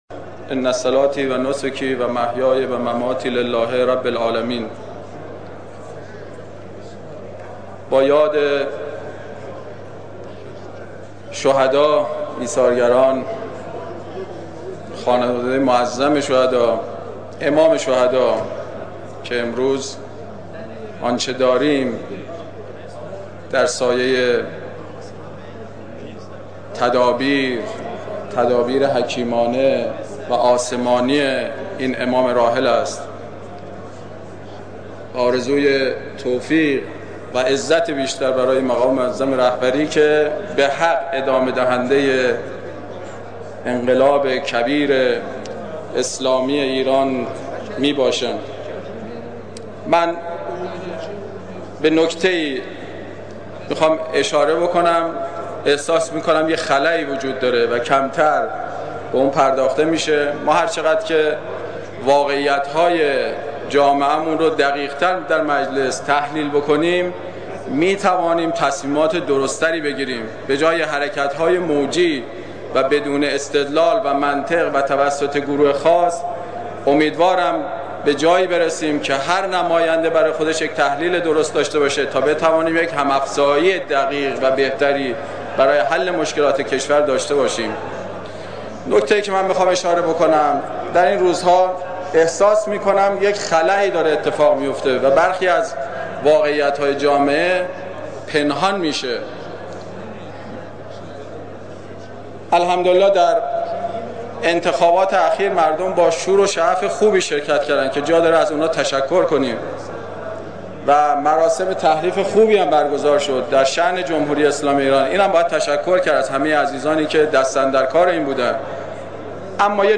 میرکاظمی در نطق میان‌دستور مطرح کرد